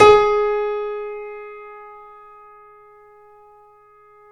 Index of /90_sSampleCDs/Roland L-CD701/KEY_YC7 Piano ff/KEY_ff YC7 Mono